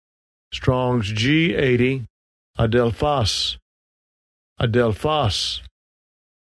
Phiên âm quốc tế: ä-del-fo’s
Phiên âm Việt: a-đeo-phót